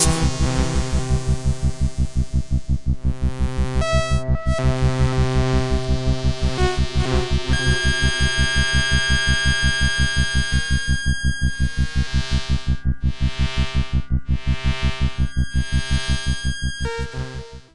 低音总是播放低音。
合成器创建具有不同特征的定义音轨。
Tag: 模拟 EP 电子 生成 合成器 EURORACK 模块化 机的组合物 合成器 噪声